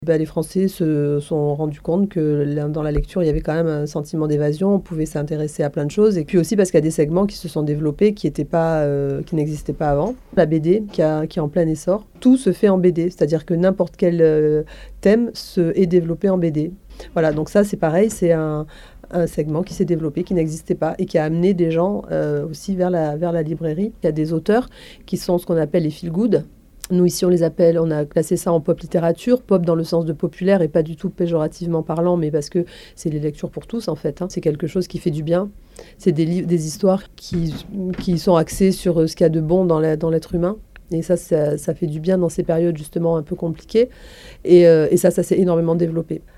Ce que cherchent les lecteurs en priorité, c’est de sortir un peu de cette actualité morose des derniers mois d’après cette libraire niçoise à notre antenne.